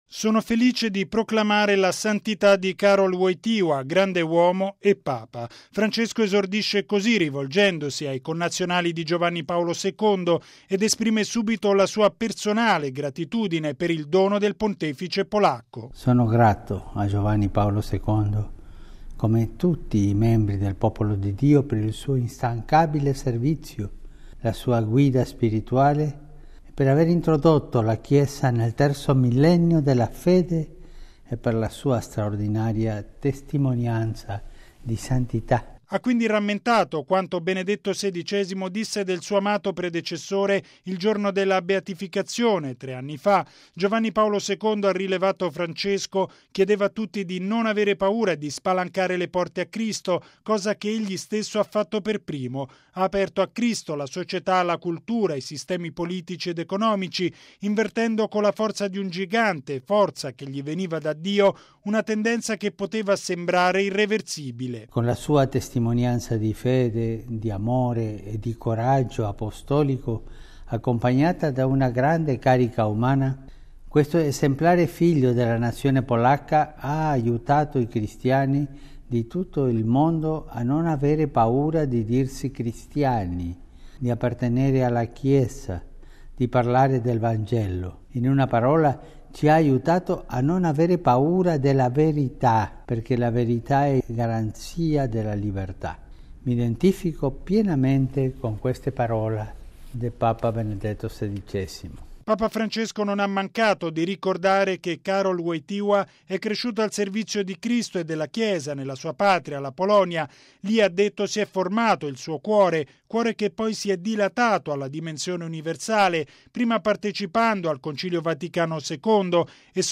E’ quanto afferma Papa Francesco in un videomessaggio, trasmesso ieri sera dalla tv e dalla radio nazionale polacca, in occasione della imminente Canonizzazione di Karol Wojtyla e Angelo Roncalli. Francesco mette l’accento sulla “straordinaria testimonianza di santità” del Pontefice polacco e sottolinea che il suo esempio continua ad essere fonte di ispirazione per tutti.